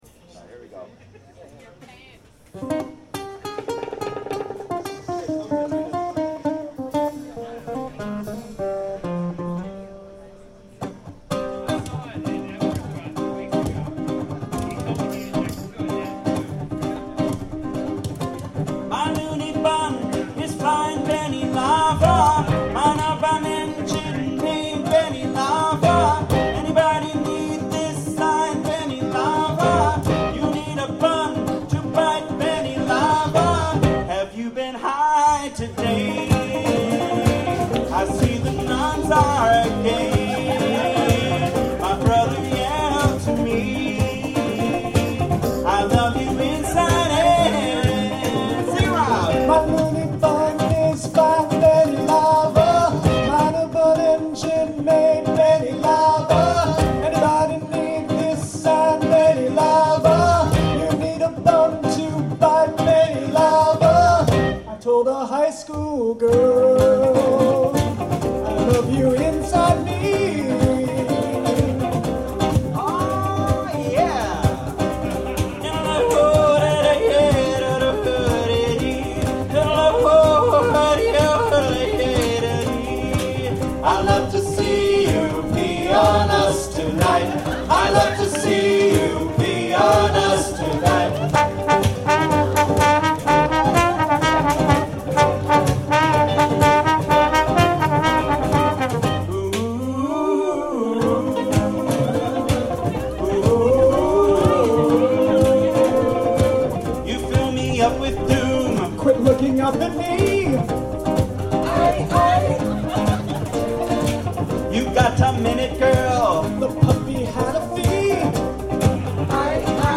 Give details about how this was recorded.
Newly home-mastered.